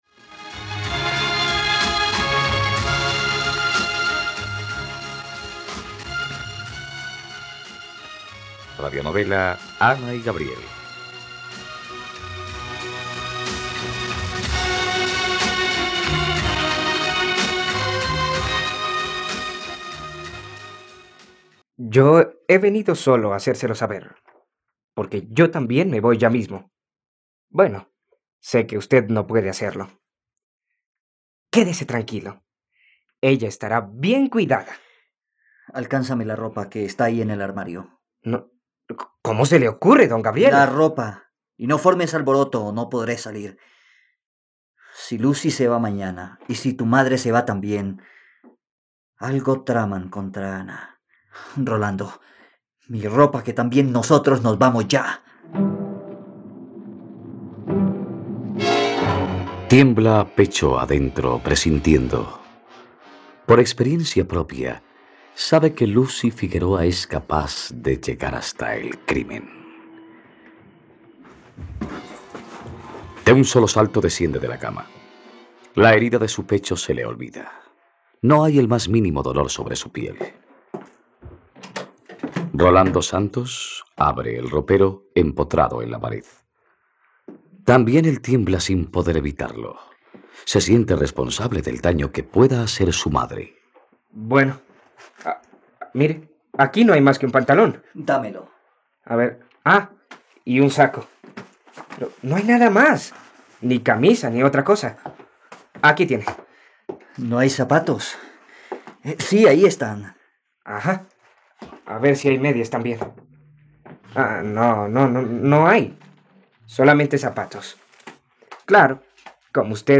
Ana y Gabriel - Radionovela, capítulo 113 | RTVCPlay